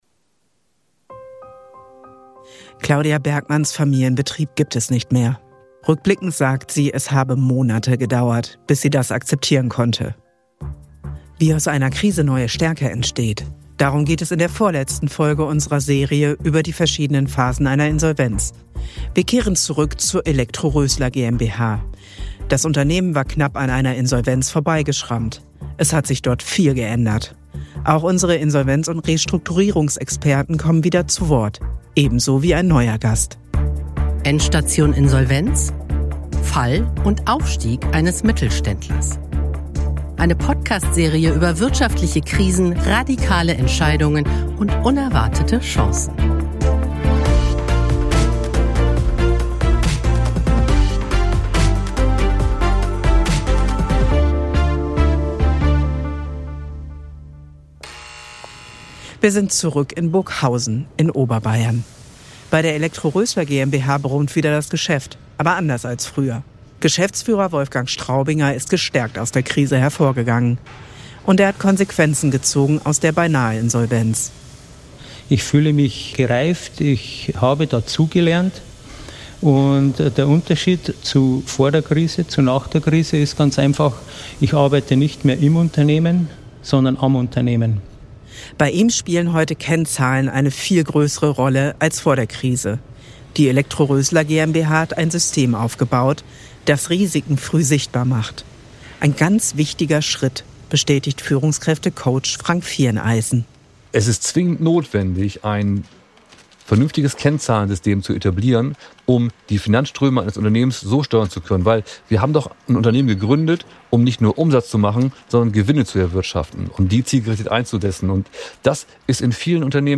Das zeigen uns Betroffene, Berater und Experten.